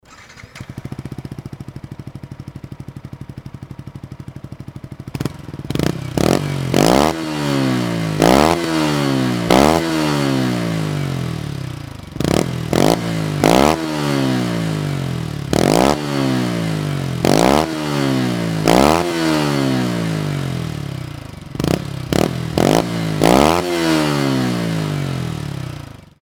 YZF-R125チタンエキパイ＋ビームスカーボンマフラー
主に低音域の音が増えていますが
音量自体は純正マフラーに毛が生えた程度なので
圧倒的に静かに抑えられていると思います。
yzf-r125_re45j-beams.mp3